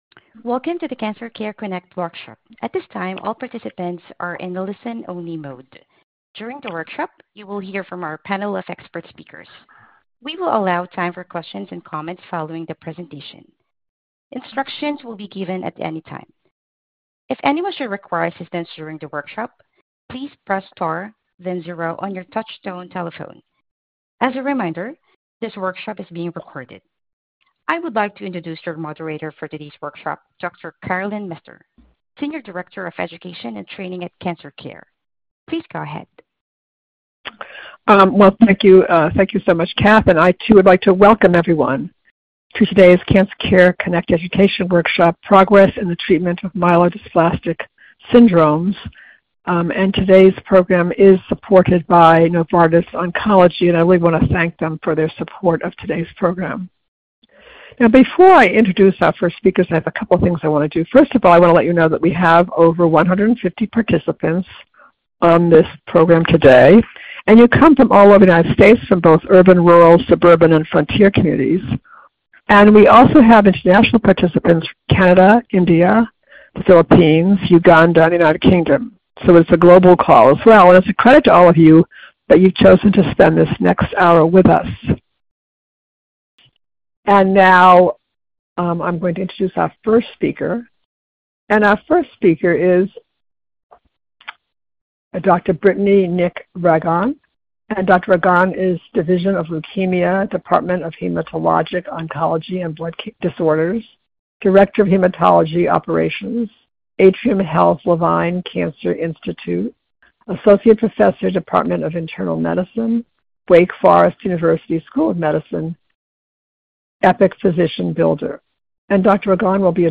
Questions for Our Panel of Experts
This workshop was originally recorded on June 23, 2025.